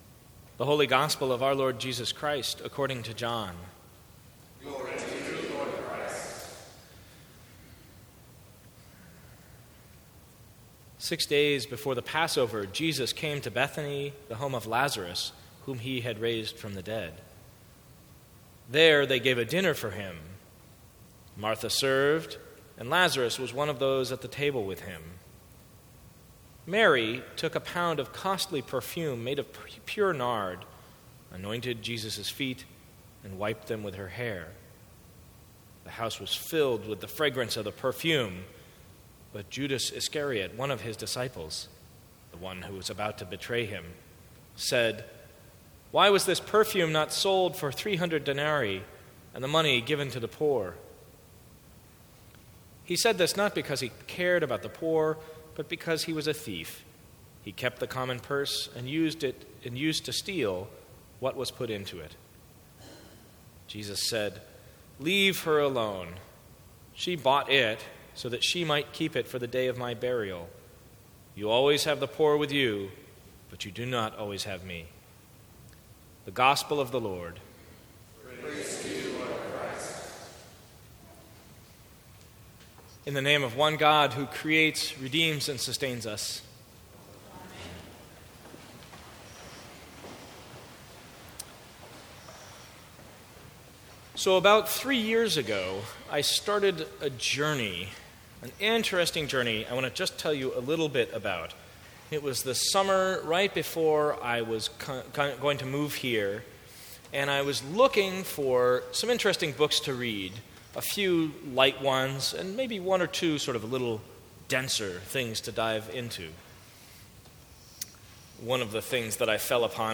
Sermons from St. Cross Episcopal Church Fifth Sunday in Lent: What about Judas?